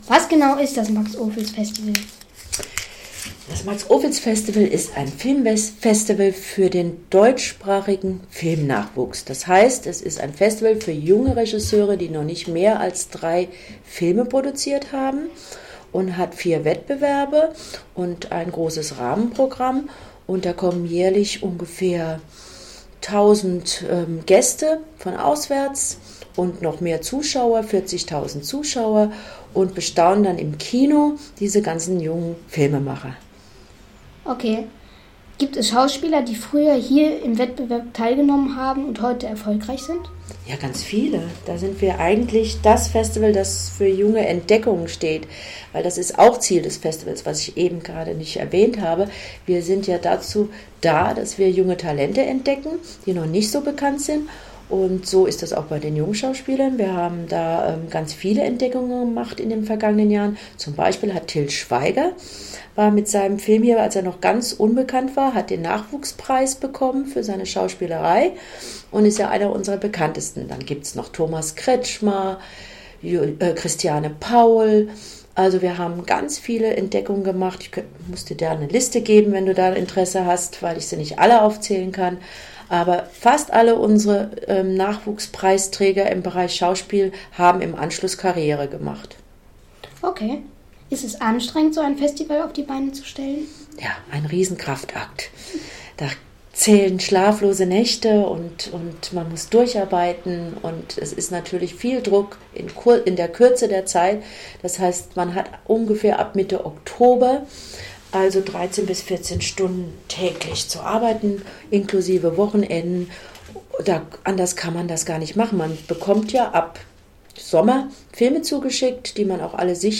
ein Interview geführt.